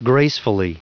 Prononciation du mot gracefully en anglais (fichier audio)
Prononciation du mot : gracefully